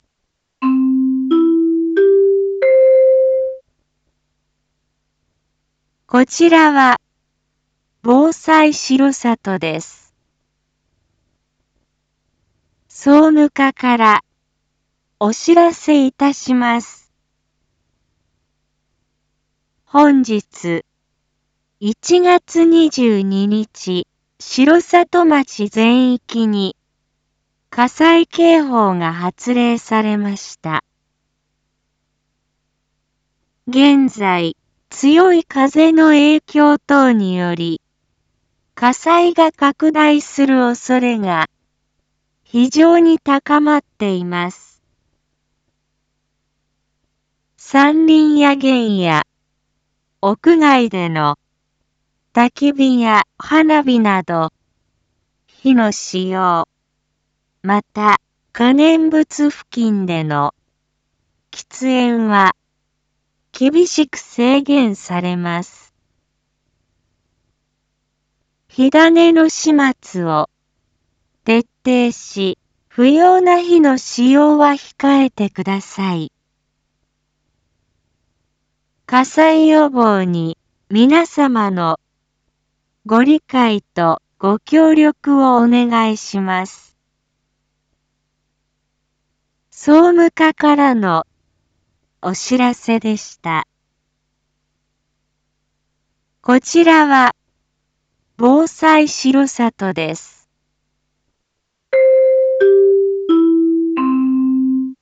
一般放送情報
Back Home 一般放送情報 音声放送 再生 一般放送情報 登録日時：2026-01-22 09:41:47 タイトル：火の取り扱いにご注意ください！（火災警報発令中） インフォメーション：令和8年1月22日9時00分現在、空気が乾燥し、火災が発生しやすい状況のため、城里町の全域に火の使用を制限する情報が発令されました。